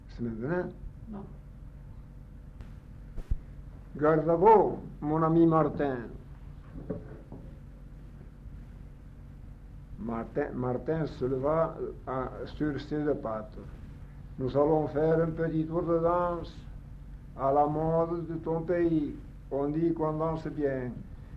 Chanson du montreur d'ours